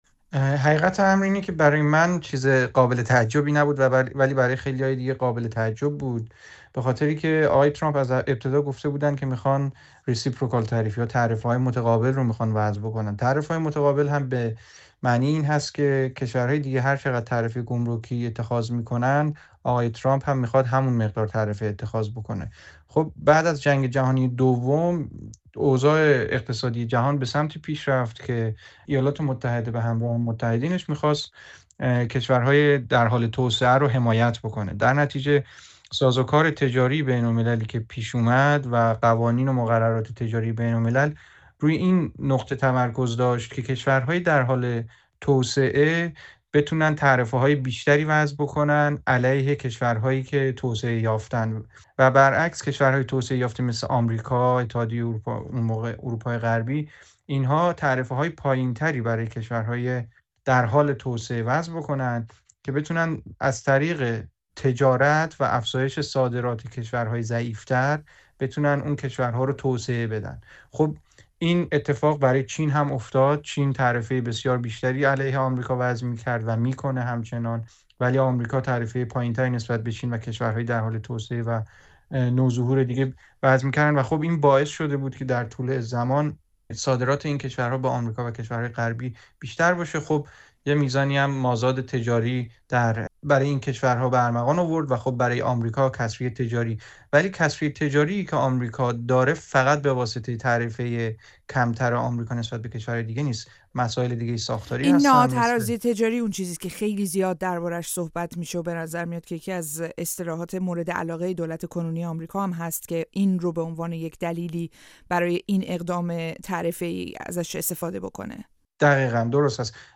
پیامد اعلام تعرفه‌های گسترده آمریکا، دلایل و چشم‌انداز در گفت‌وگو